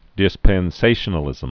(dĭspĕn-sāshə-nə-lĭzəm)